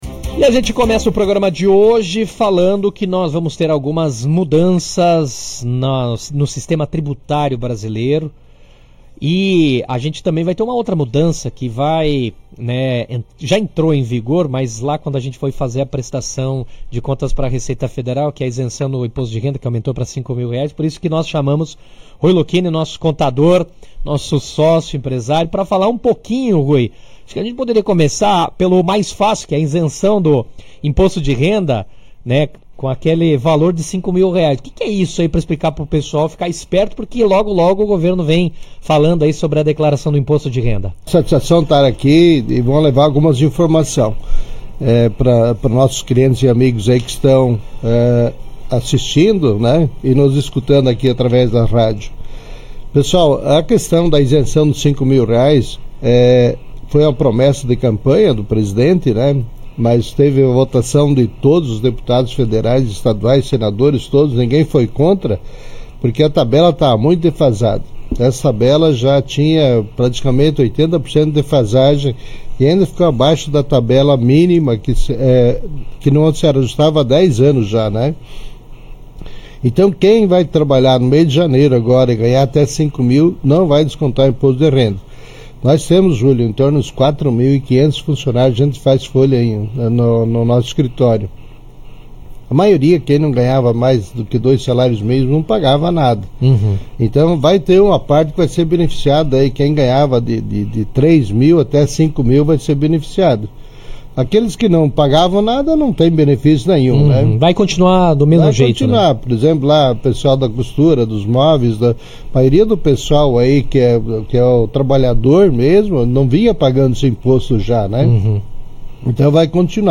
Segundo o especialista, a medida representa um alívio importante para a classe média, porém o governo deverá compensar a perda de arrecadação por meio de uma tributação mais rigorosa sobre lucros, dividendos e rendimentos mais elevados. Durante a entrevista